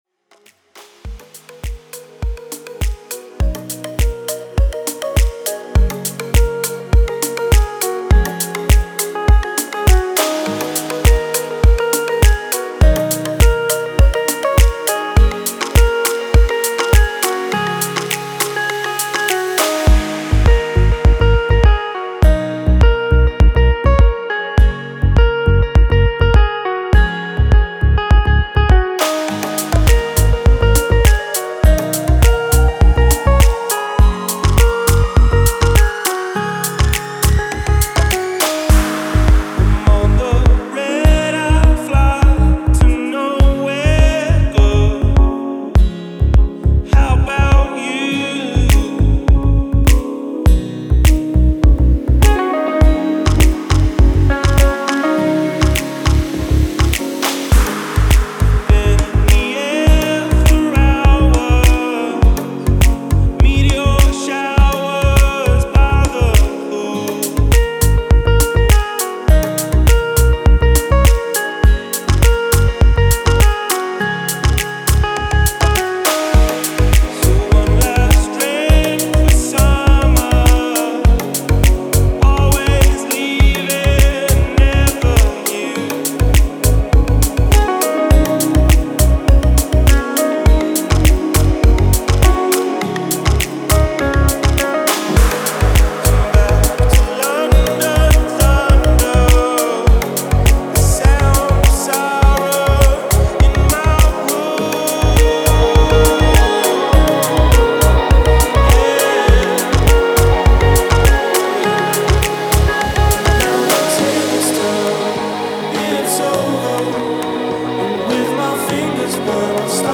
Спокойная музыка
релакс музыка